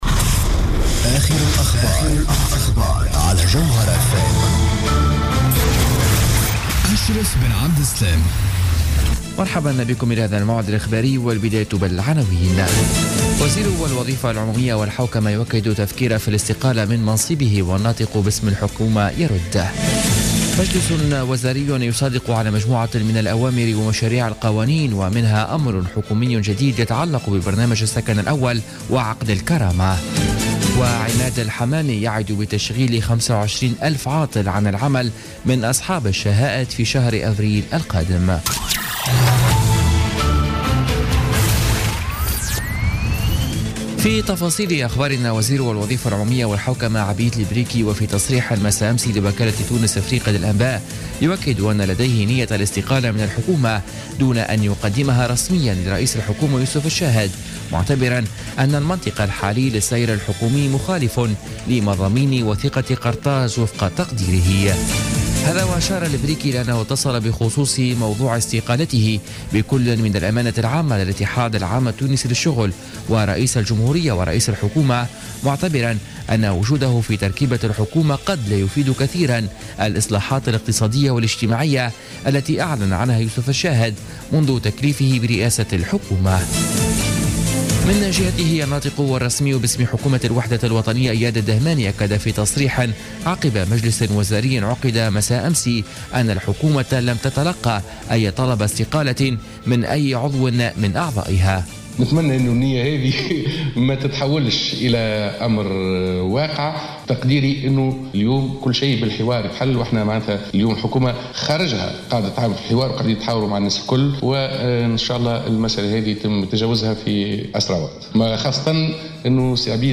نشرة أخبار منتصف الليل ليوم السبت 25 فيفري 2017